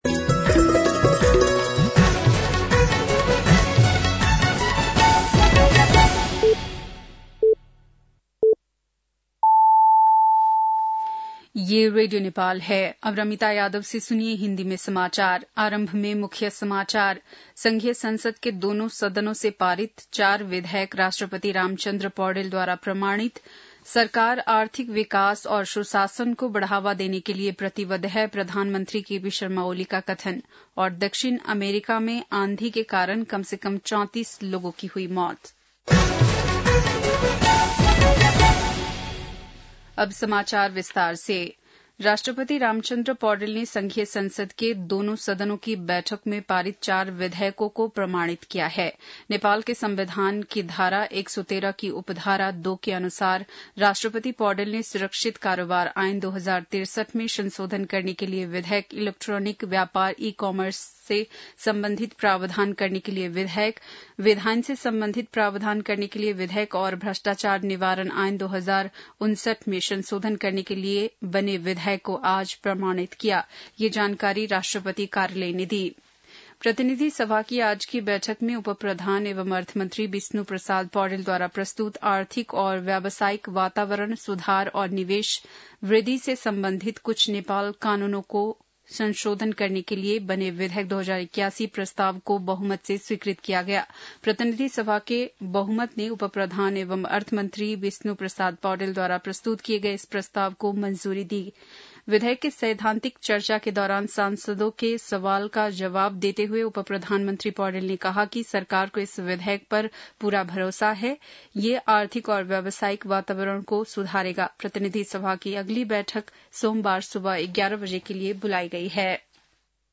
बेलुकी १० बजेको हिन्दी समाचार : ३ चैत , २०८१